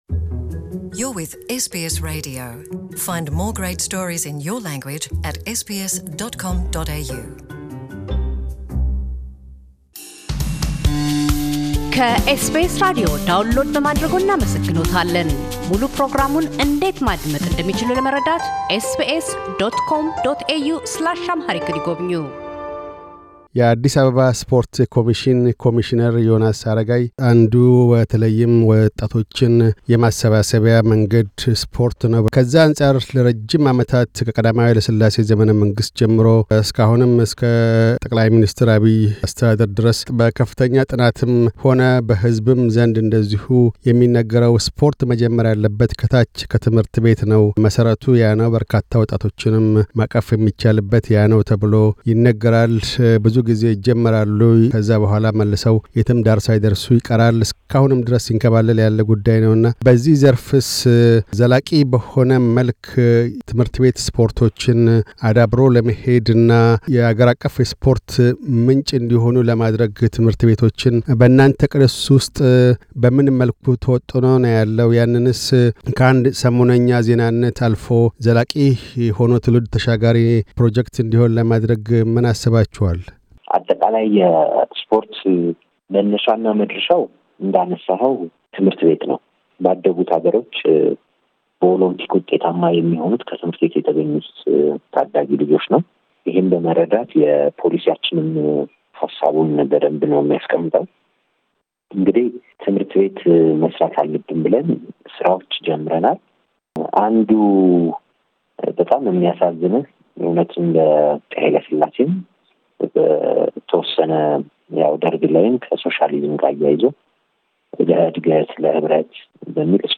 የአዲስ አበባ ስፖርት ኮሚሽን - ኮሚሽነር ዮናስ አረጋይ፤ ኮሚሽኑ አዲስ አበባን ዳግም የኢትዮጵያ ስፖርት መዲና ለማድረግ እንደሚሰራ፣ ስፖርትን ለማማስፋፍት ከትምህርት ቤቶች የመጀመር ውጥኖችን በግብር ስለመጀመር፣ የማስ ስፖርት፣ የአካል ጉዳተኞች ፌስቲቫል ሂደቶችን አንስተው ይናገራሉ።